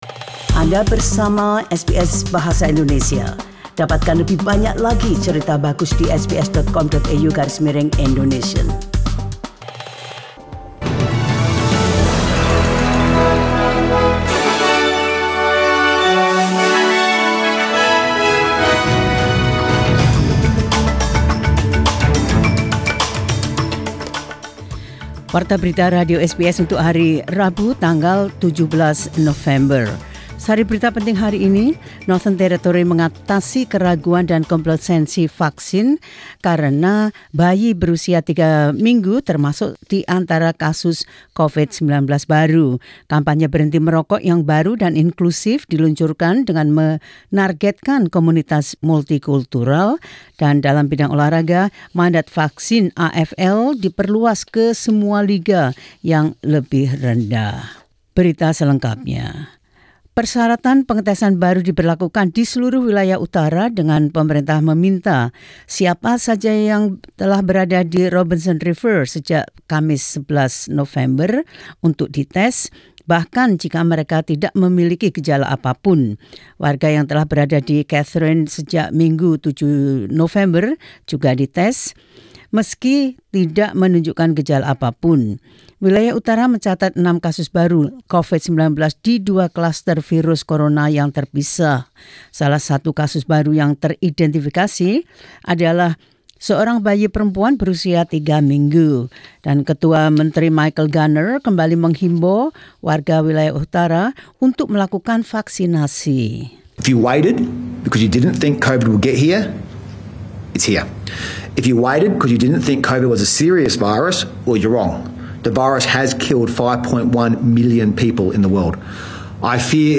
Warta Berita Radio SBS Program Bahasa Indonesia – 17 Nov 2021